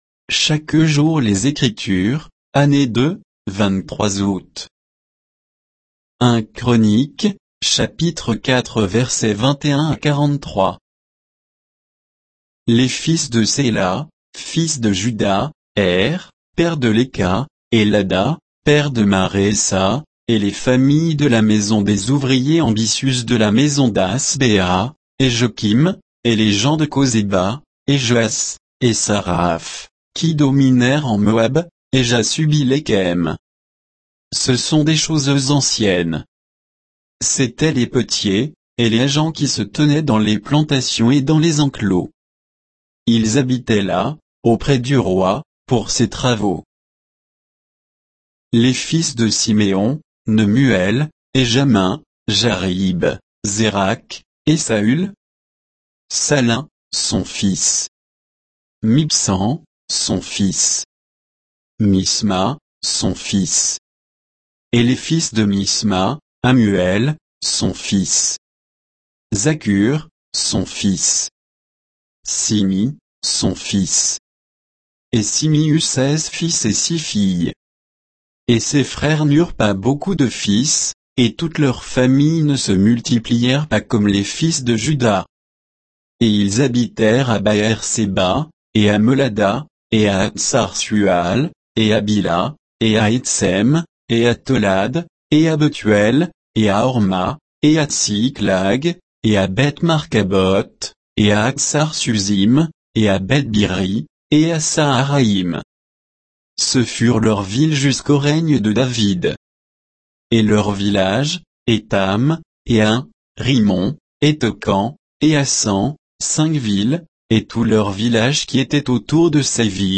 Méditation quoditienne de Chaque jour les Écritures sur 1 Chroniques 4